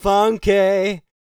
TB SING 302.wav